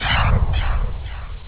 woosh.wav